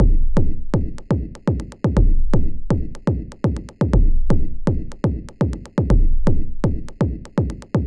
A# (B Flat Major - 6B) Free sound effects and audio clips
• percussion synth massive 2 A#.wav
Oscar Mulero, Exium, Go Hyiama, Rene Wise type, clicky and clear sound techno kick and percussion artefacts.